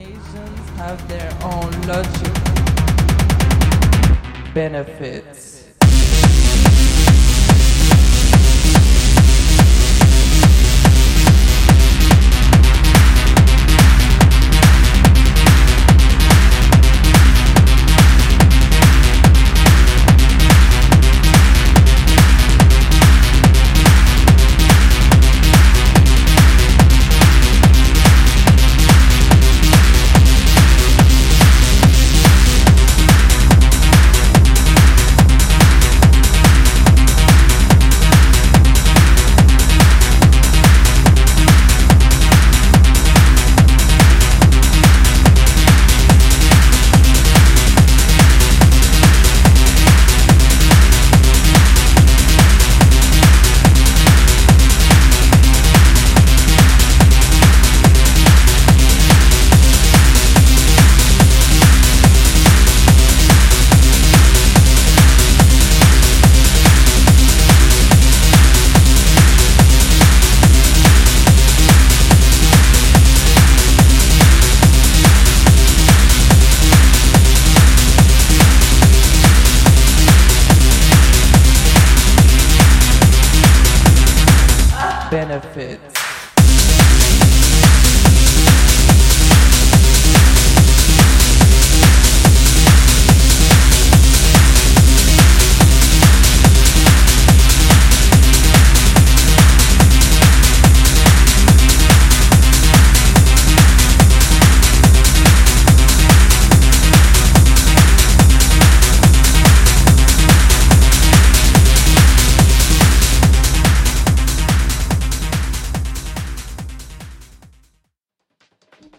Storming, rapid-fire 90's techno.